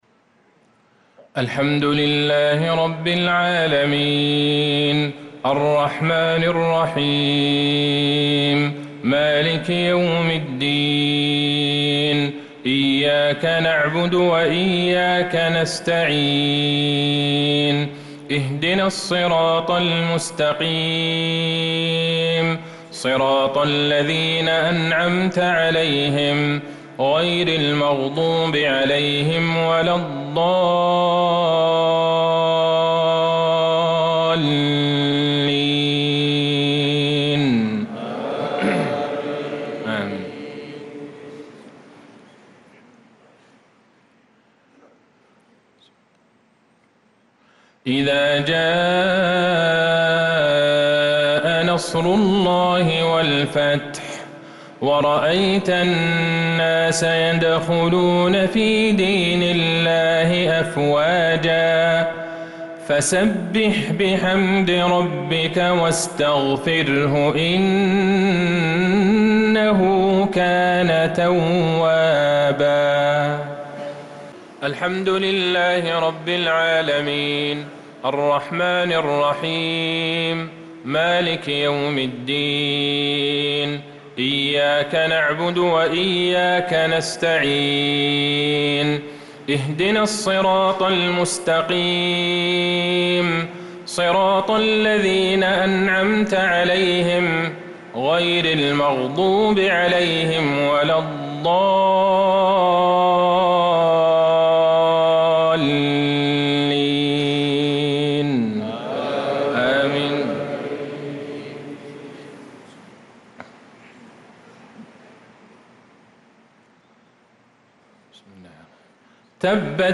صلاة المغرب للقارئ عبدالله البعيجان 25 ذو الحجة 1445 هـ
تِلَاوَات الْحَرَمَيْن .